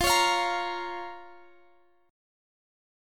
Listen to C#6/F strummed